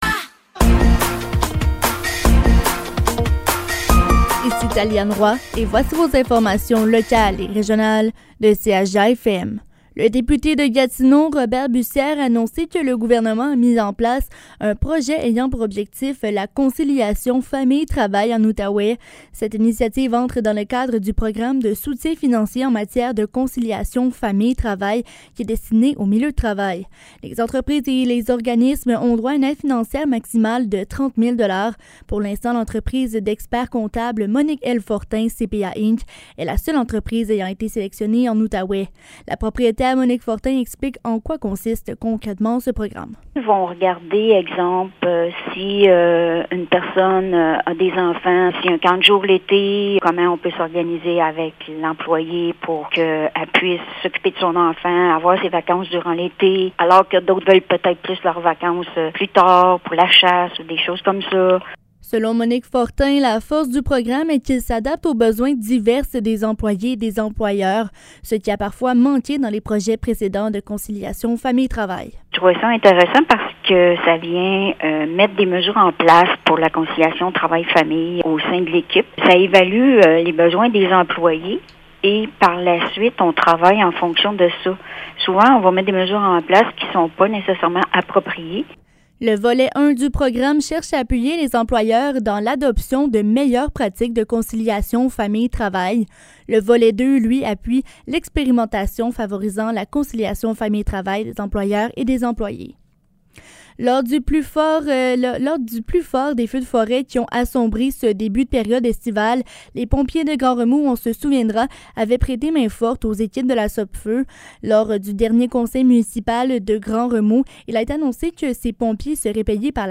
Nouvelles locales - 25 juillet 2023 - 15 h